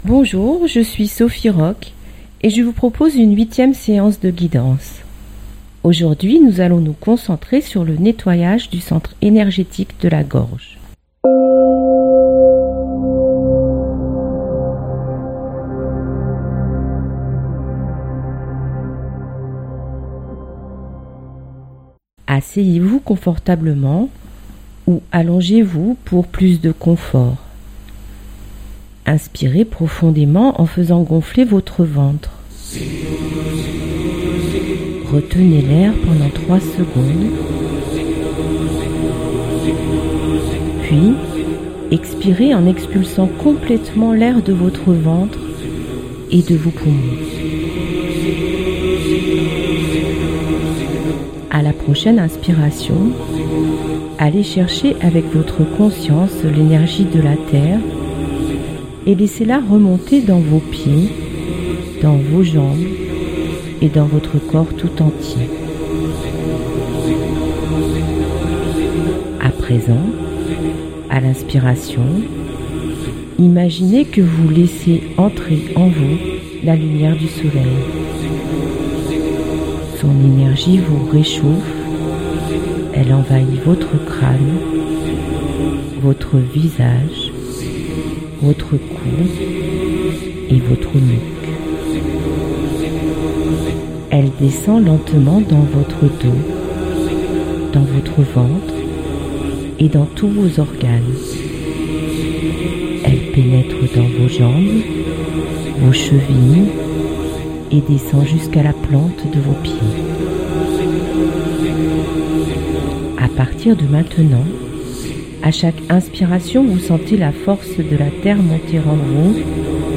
Sur les ondes de fréquence mistral, chaque semaine, je vous propose l'émission " En chemin vers soi " chaque mardi à 8h40 en direct afin d'expérimenter des séances très simples : de respiration, de méditation, de visualisation. Le but de ces séances est de vous ramener vers votre intériorité, de vous aider à retrouver votre centre pour mieux vous sentir dans votre vie de tous les jours.